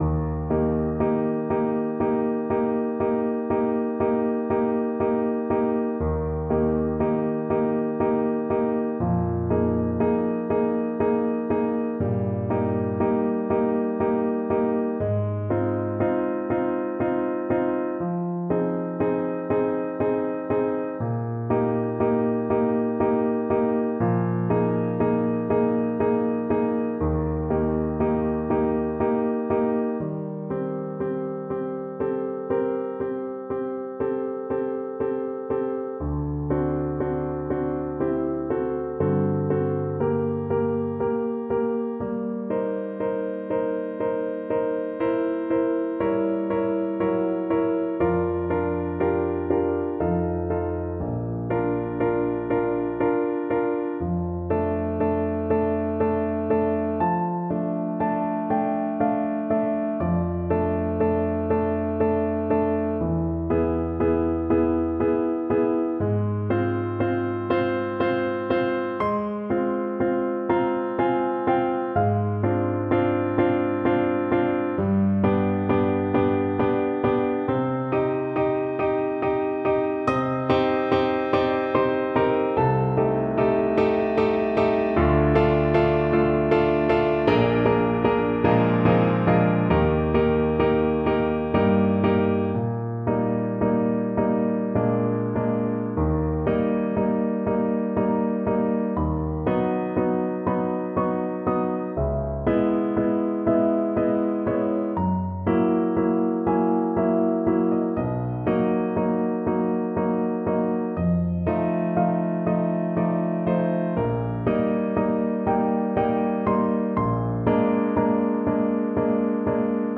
Andante (=c.60)
3/4 (View more 3/4 Music)
Bb4-G6
Classical (View more Classical Voice Music)